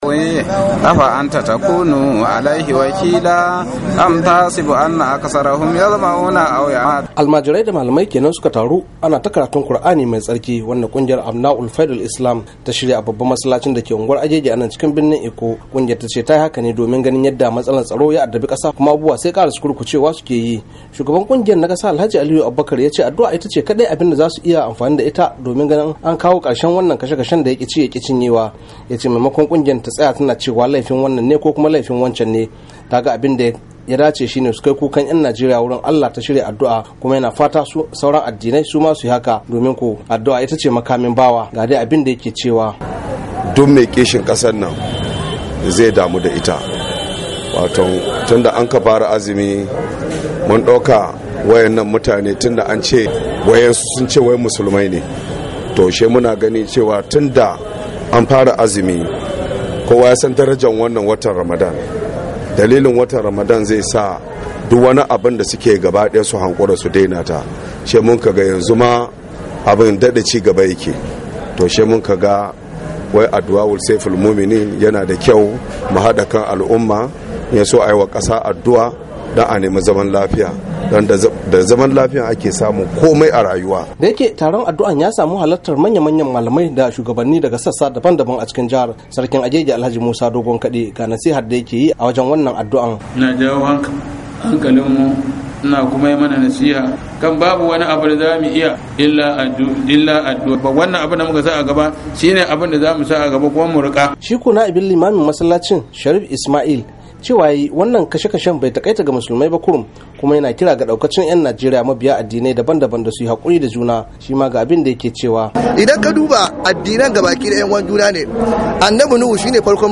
Almajirai da malamai suka taru ana ta karatun Kur'ani mai tsarki wanda wata kungiyar Islama ta shirya a Masallacin dake unguwar Agege dake birnin Legas.